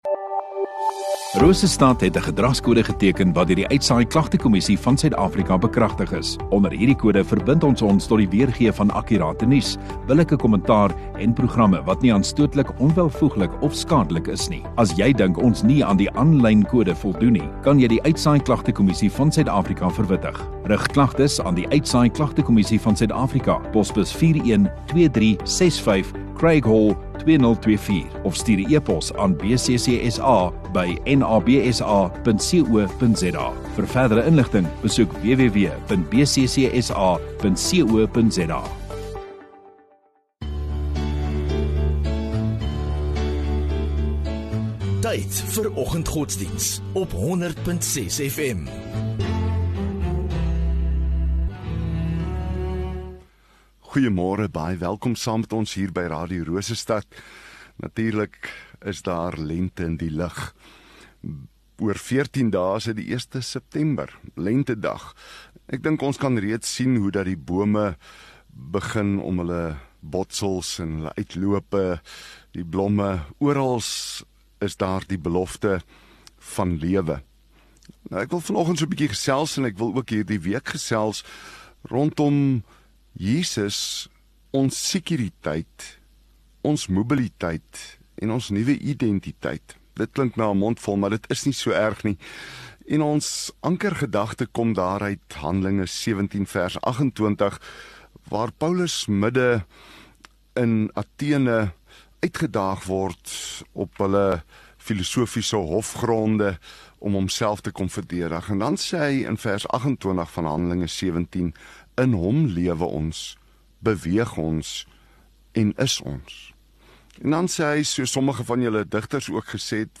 18 Aug Maandag Oggenddiens